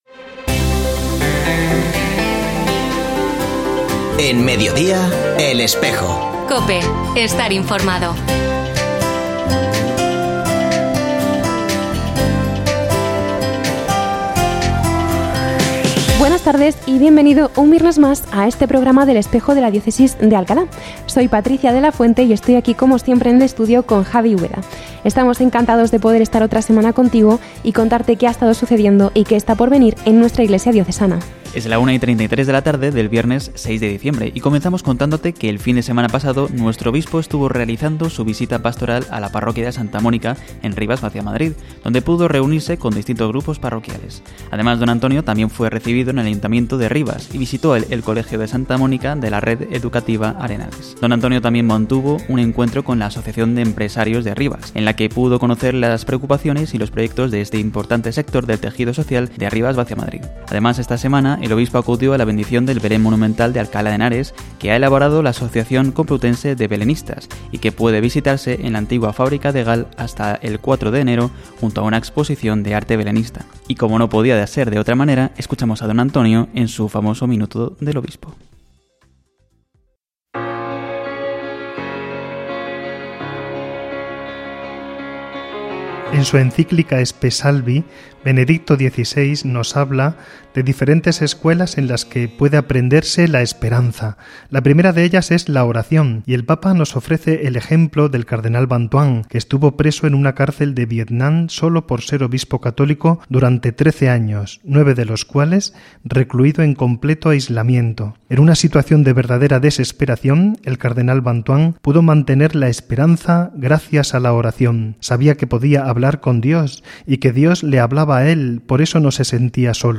Escucha otras entrevistas de El Espejo de la Diócesis de Alcalá
Se ha vuelto a emitir hoy, 6 de diciembre de 2024, en radio COPE. Este espacio de información religiosa de nuestra diócesis puede escucharse en la frecuencia 92.0 FM, todos los viernes de 13.33 a 14 horas.